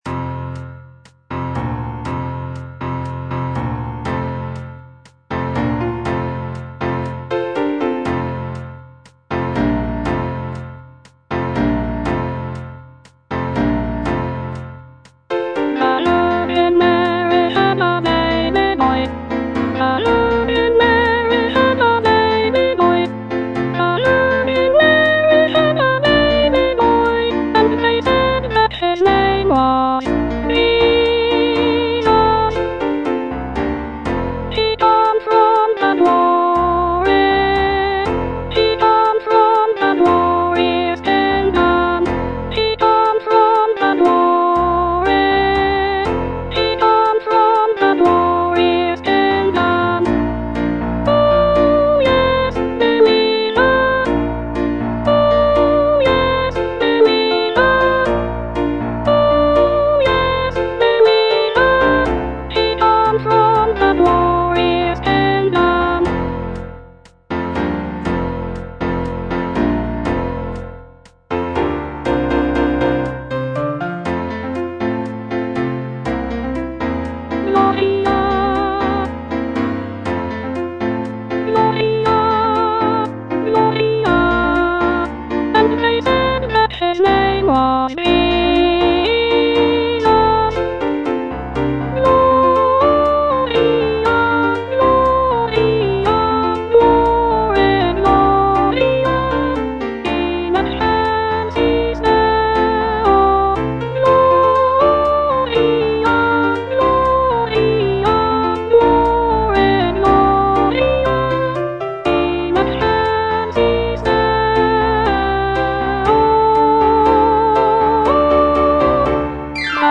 Soprano II (Voice with metronome)
" set to a lively calypso rhythm.
incorporating Caribbean influences and infectious rhythms.